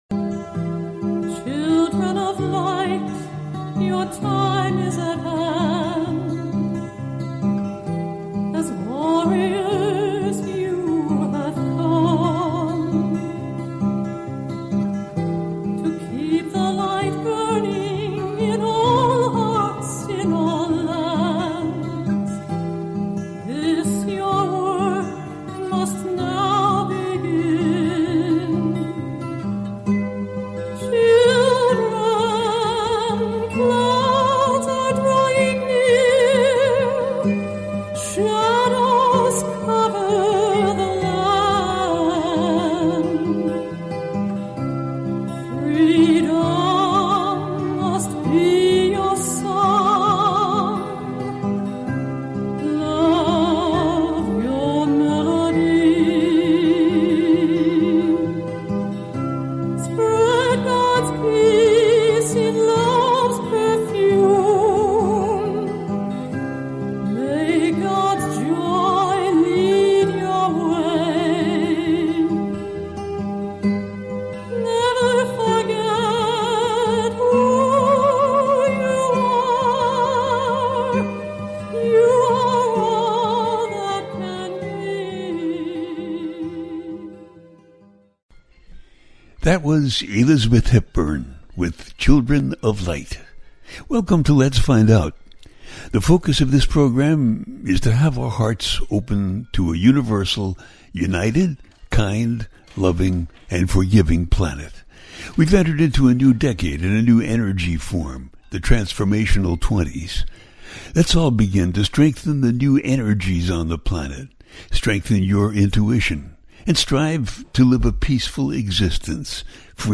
Talk Show Episode
A teaching show.